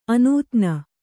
♪ anūtna